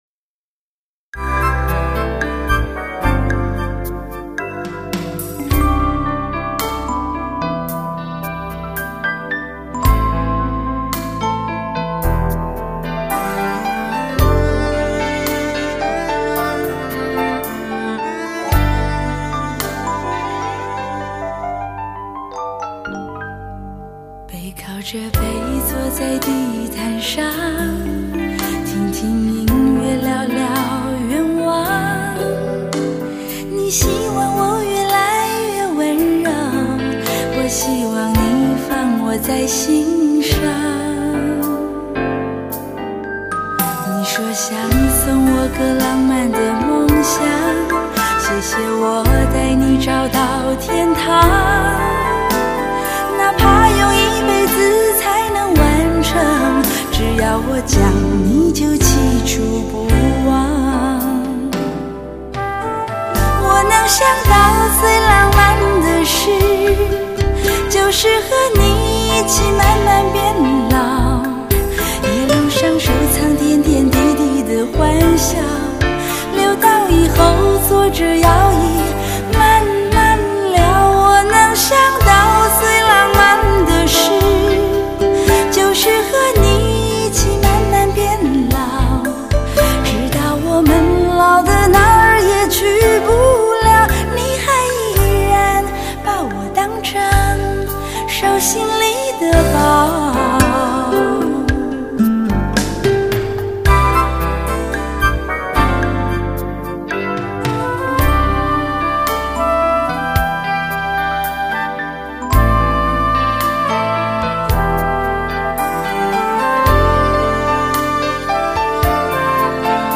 沙沙的、柔柔的、甜甜的